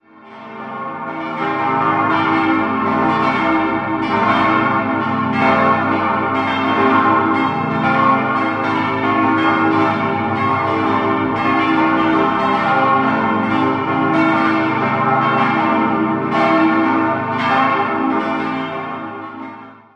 So wurde 1785 abermals mit dem Bau einer neuen, vierten Kirche begonnen, die heute noch existiert. 6-stimmiges Geläute: g°-a°-c'-e'-g'-a' Die Glocken 1, 2, 4 und 5 wurden 1935 von der Gießerei Staad in Rorschach gegossen, die beiden anderen stammen von Rüetschi aus dem Jahr 1988.